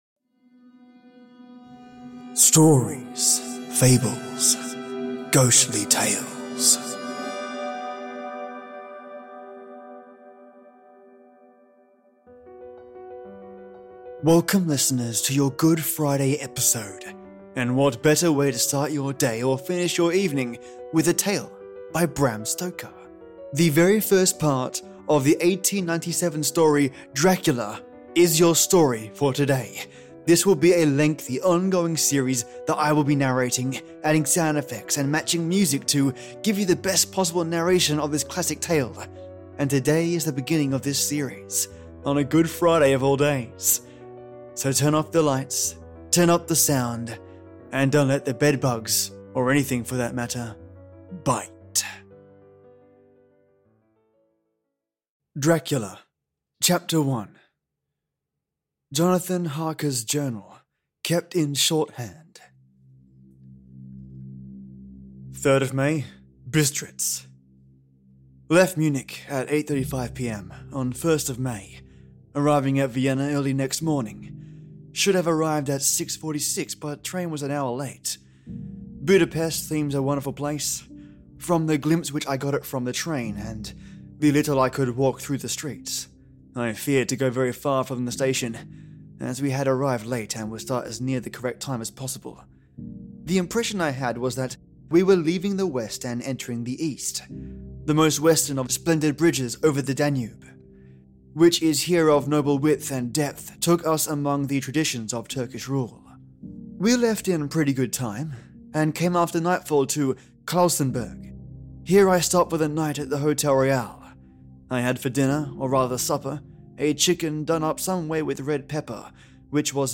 546: Dracula by Bram Stoker | Audio Book | Dramatised | DOLPHIN N COW